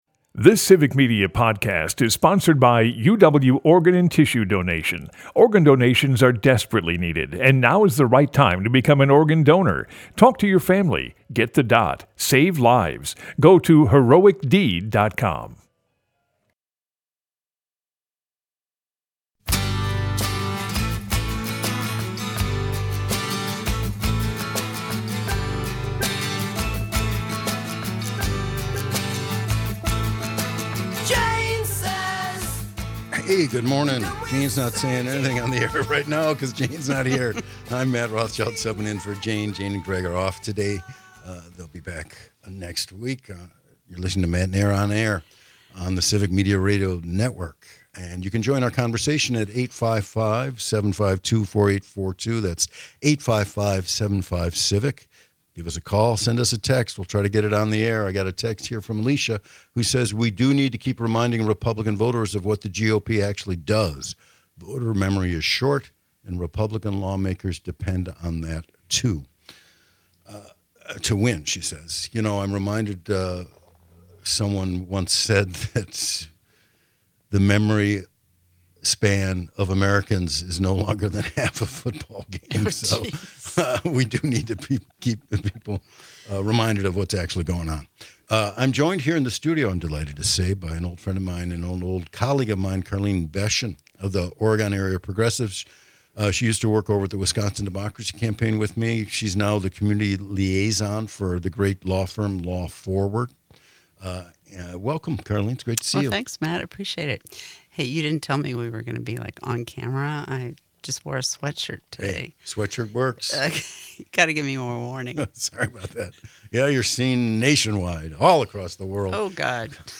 They cover all the news that affects you with humor and a unique perspective.